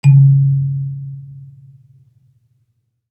kalimba_bass-C#2-pp.wav